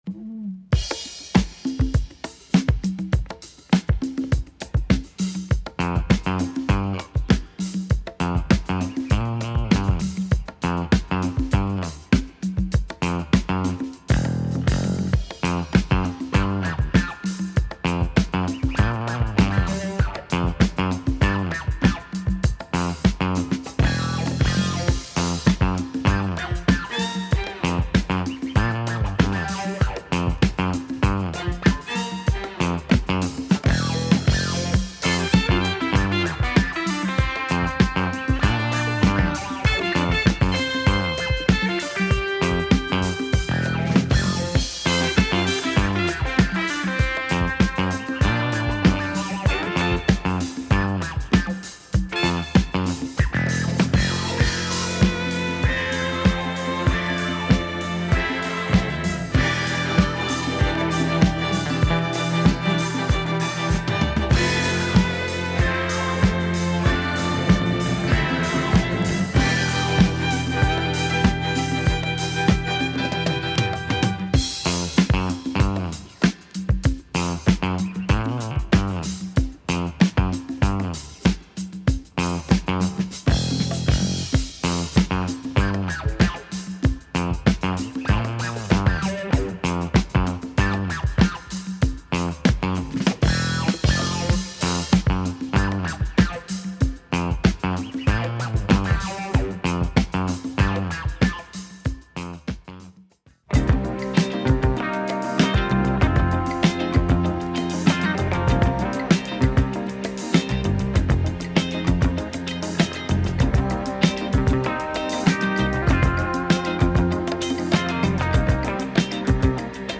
Killer funk British library
The cop show theme from London !